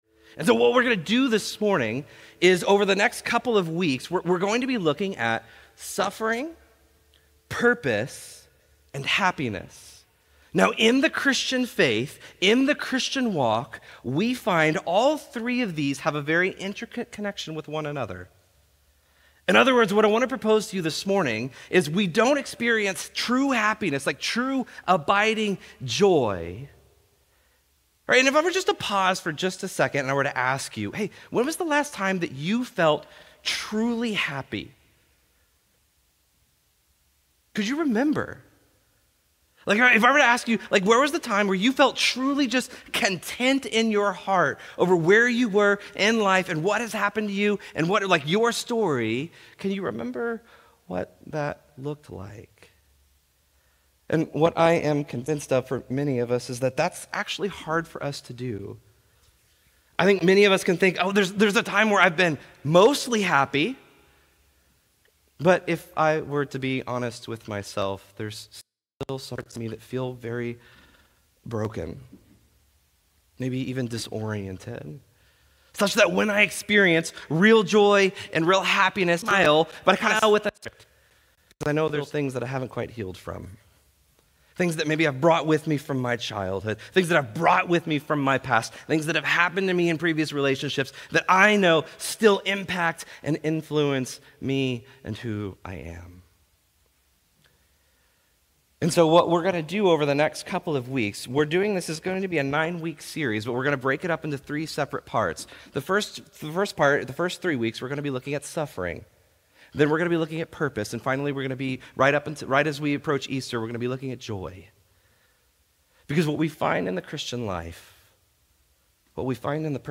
Restore Houston Church Sermons